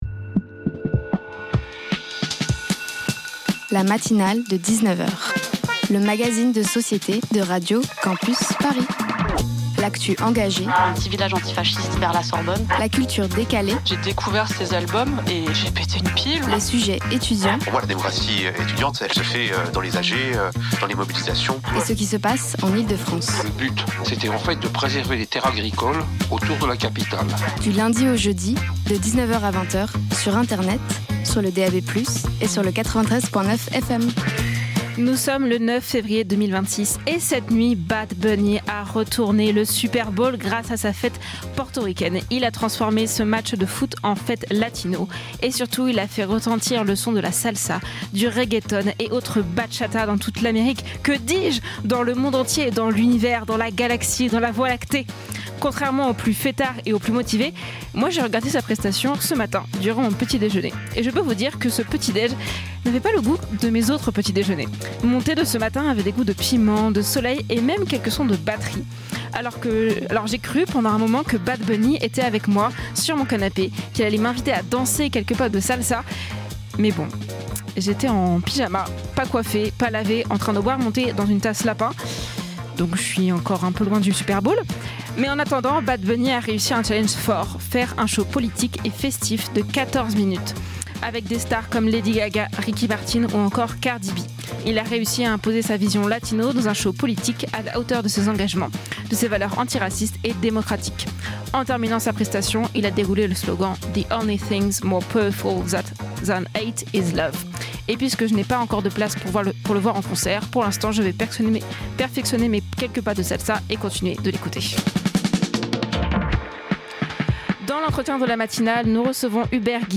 Les algorithmes contre la société & Emancipation féminine Partager Type Magazine Société Culture lundi 9 février 2026 Lire Pause Télécharger Ce soir